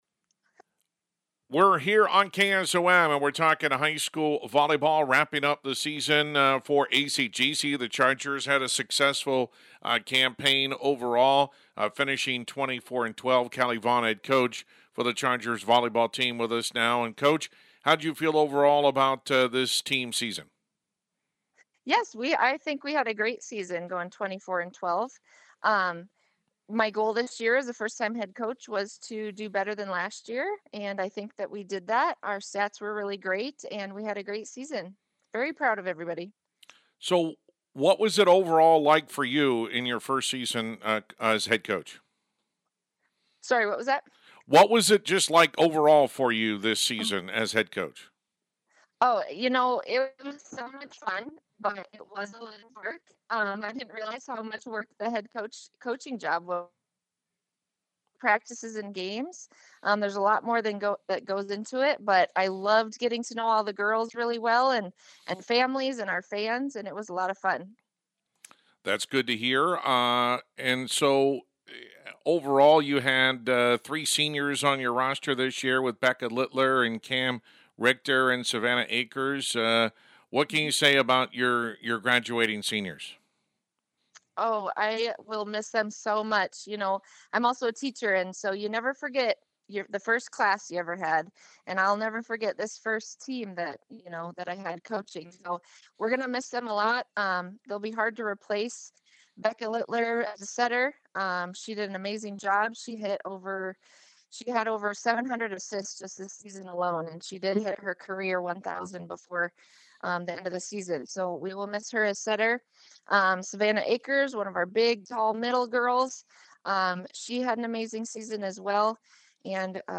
acgc-volleyball-11-10-final.mp3